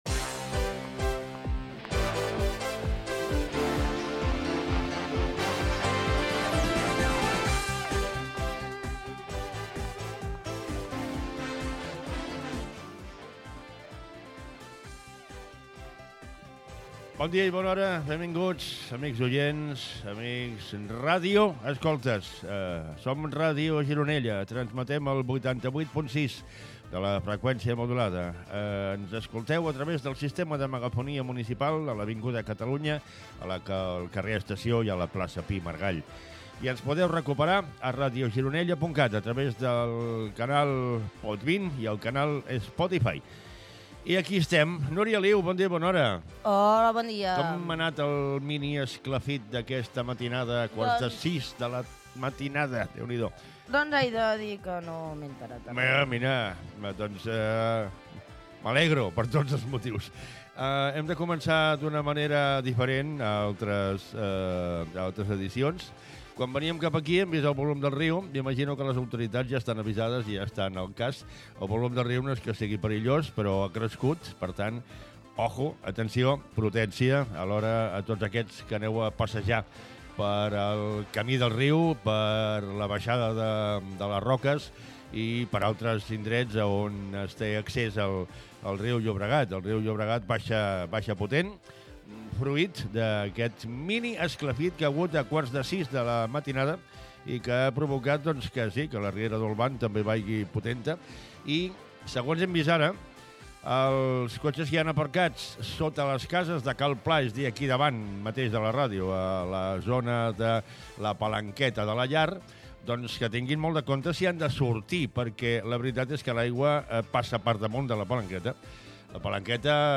Llocs per on es pot escoltar la ràdio, comentari sobre la crescuda del cabal del riu Llobregat, hora, data, sumari del programa, dades meteorològiques i de l'agua de l'envassament de la Baells, dades astronòmiques, farmàcia de guàrdia, Record cinematogràfic del cinema de Gironella, resum informatiu de la setmana a Gironella i Berguedà Gènere radiofònic Info-entreteniment